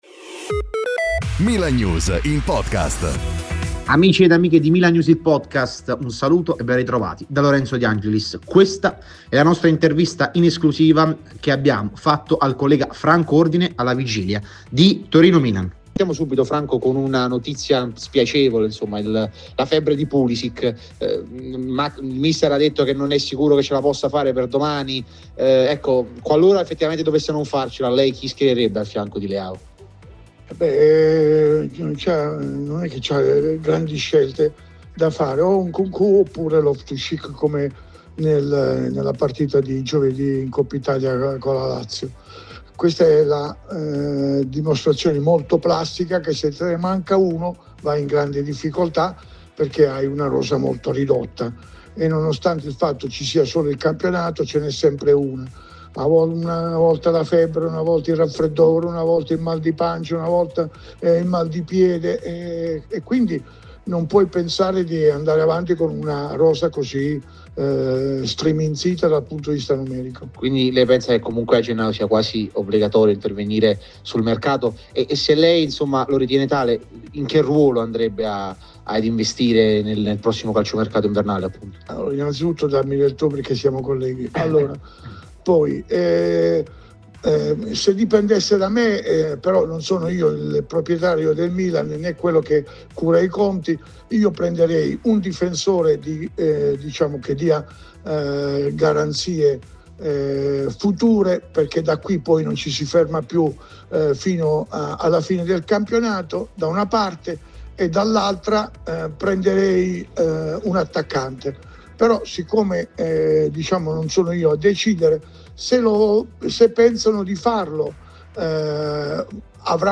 fonte intervista